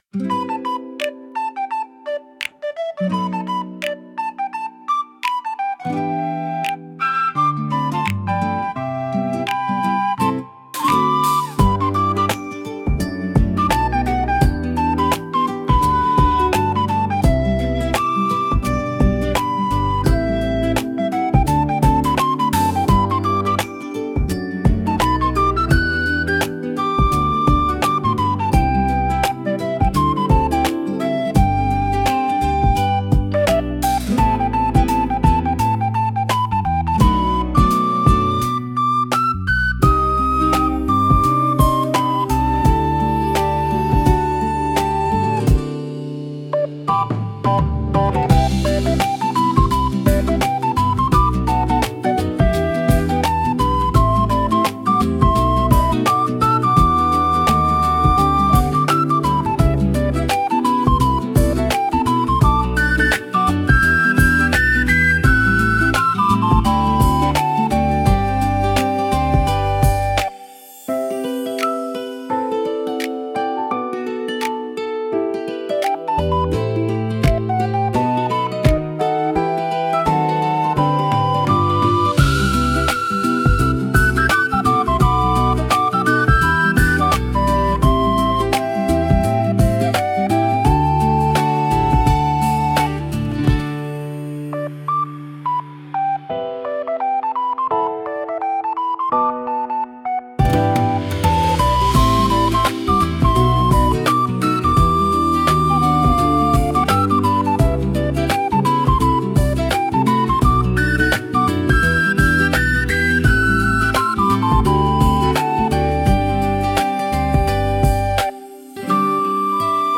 かわいい , ほのぼの , コミカル , ムービー , リコーダー , 朝 , 秋 , 穏やか